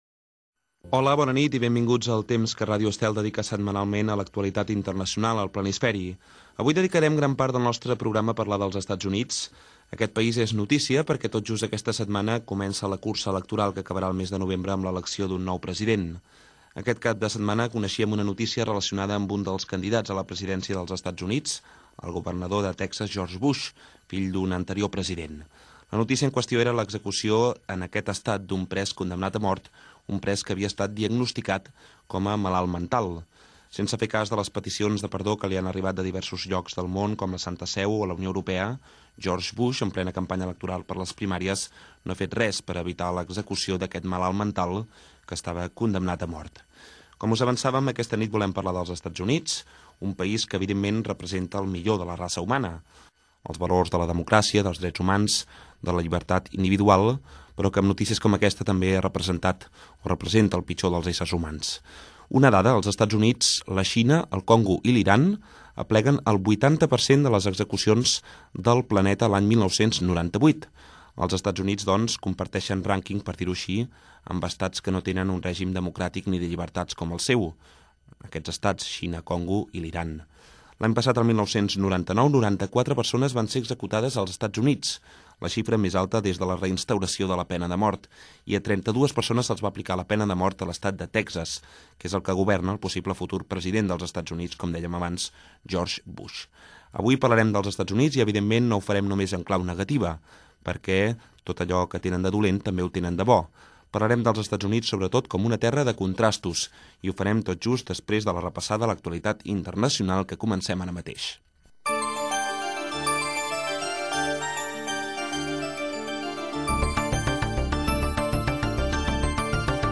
Comentari i informació sobre la pena de mort, repàs a l'actualitat internacional, indicatiu del programa, dades sobre la immigració als Estats Units Gènere radiofònic Informatiu